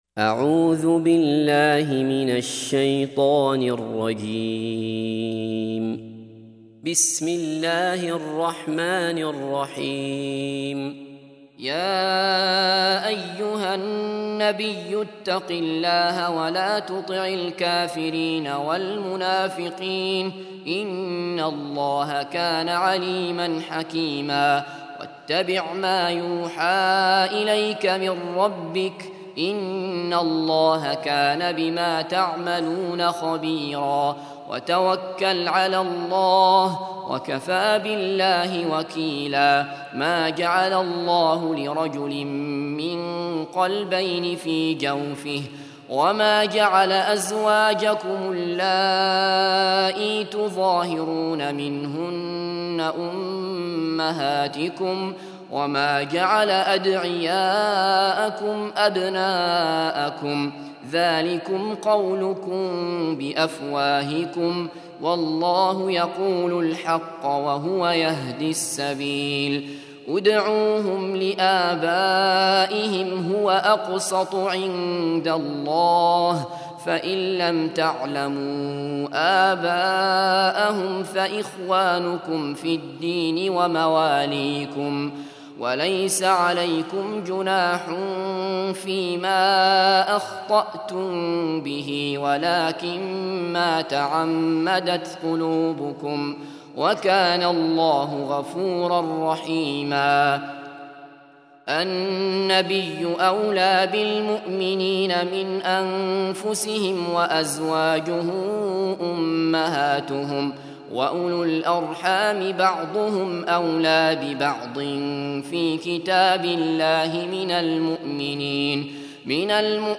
33. Surah Al�Ahz�b سورة الأحزاب Audio Quran Tarteel Recitation
Surah Repeating تكرار السورة Download Surah حمّل السورة Reciting Murattalah Audio for 33.